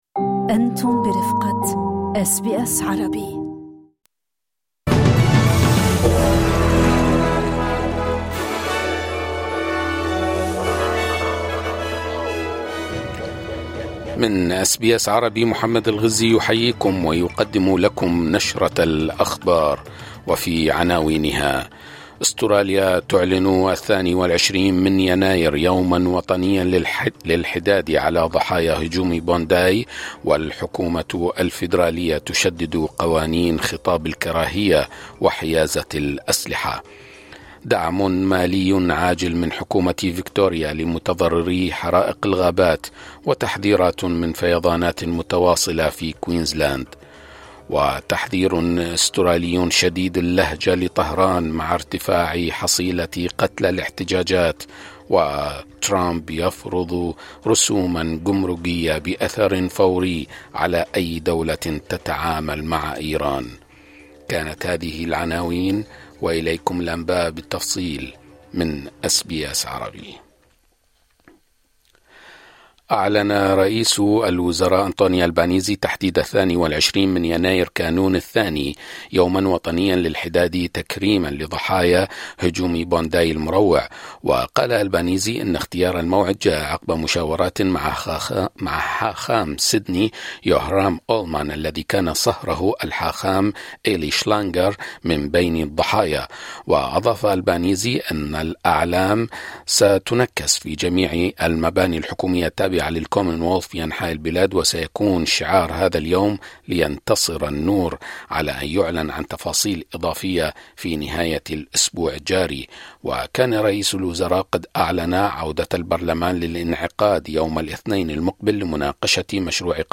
نشرة أخبار المساء 13/01/2026